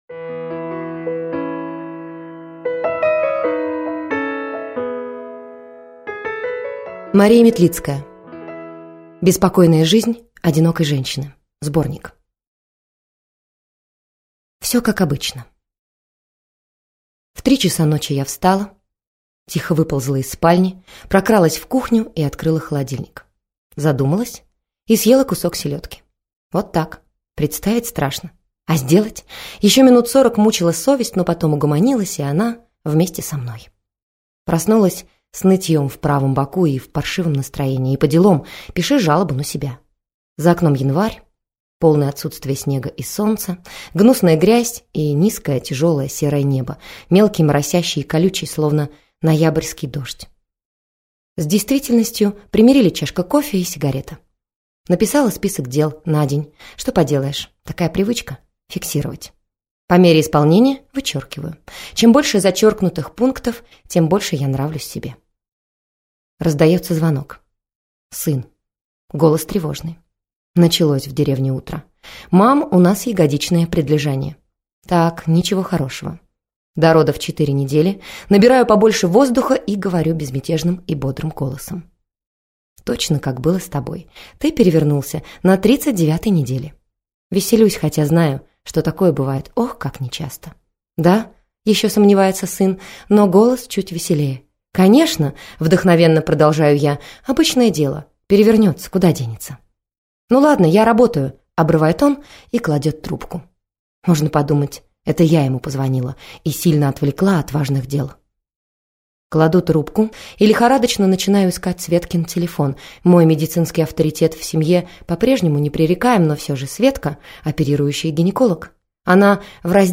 Аудиокнига Беспокойная жизнь одинокой женщины | Библиотека аудиокниг